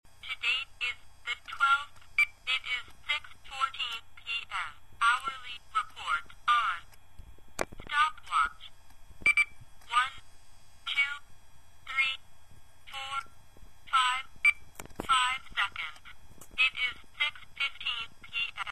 Loaded with Features, Clear Voice and Lightweight
• Features clear female voice; digital.
talking_sports_watch.mp3